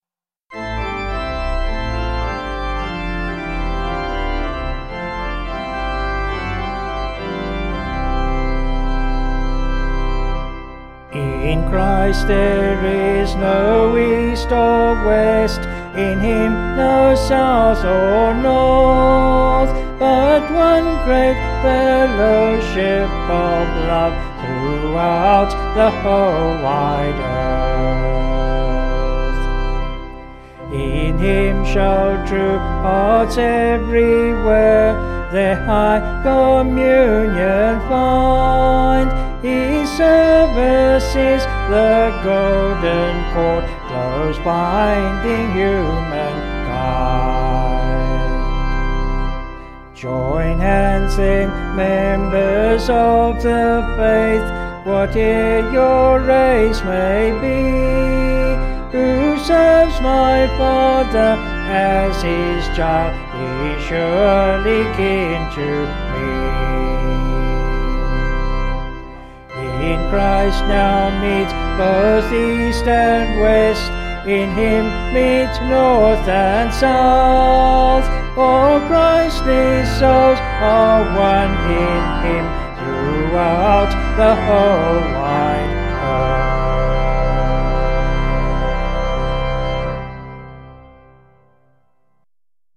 Vocals and Organ   263.7kb Sung Lyrics